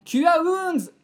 呪文 魔法 ボイス 声素材 – Magic Spell Voice